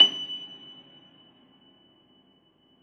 53g-pno24-F5.wav